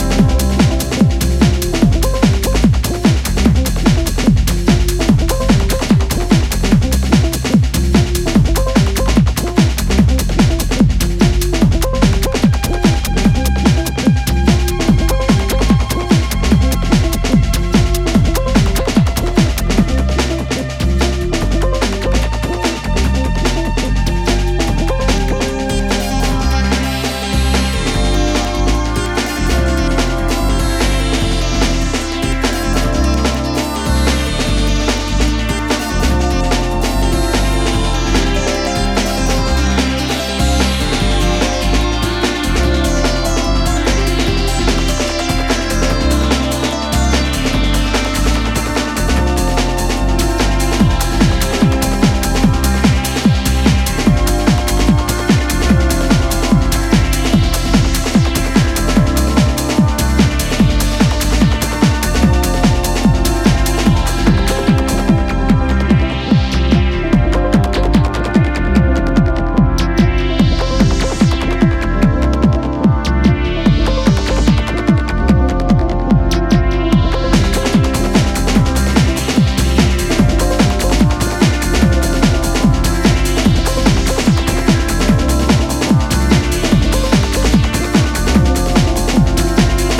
Electro Electronix Techno